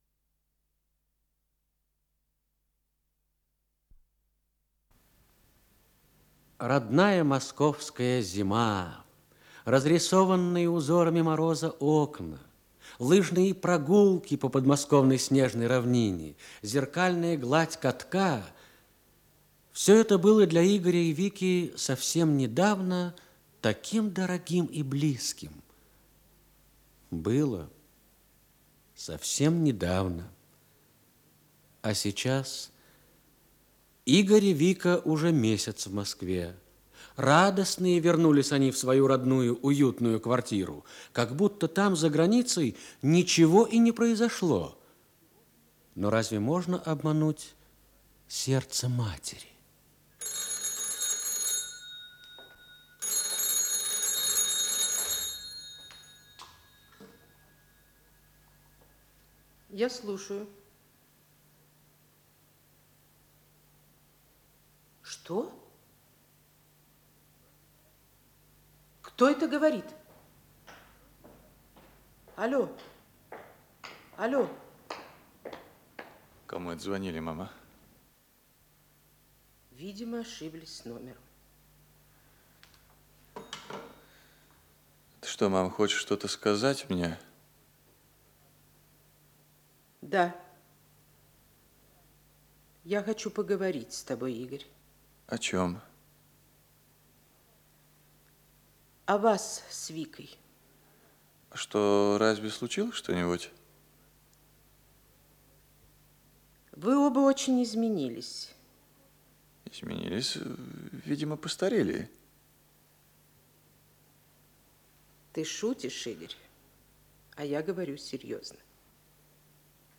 Исполнитель: Артисты Московского театра им. Ленинского комсомола
Радиокомпозиция